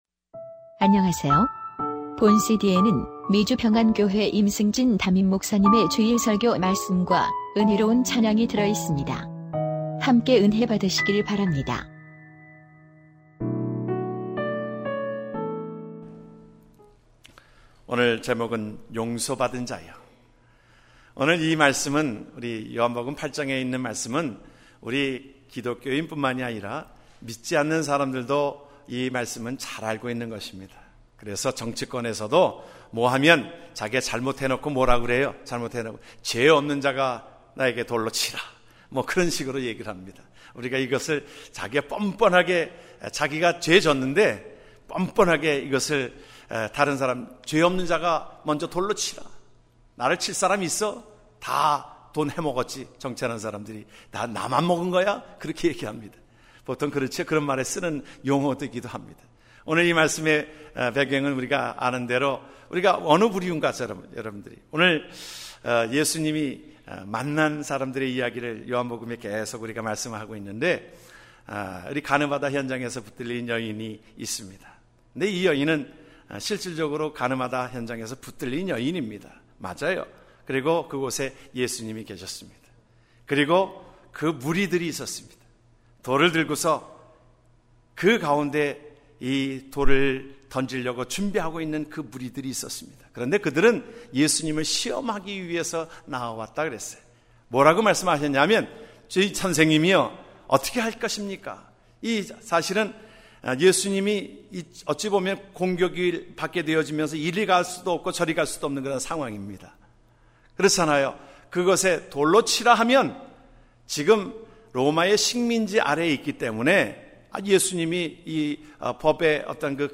2015년 6월 28일 미주평안교회 주일설교말씀: 용서함을 받은 자여(요 8:1-11절)